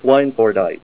Say SWINEFORDITE